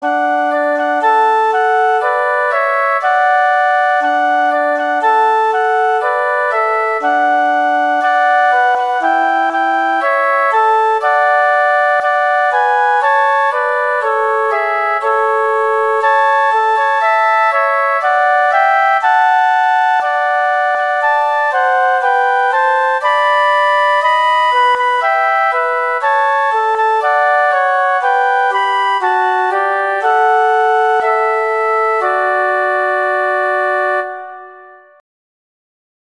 Instrumentation: two flutes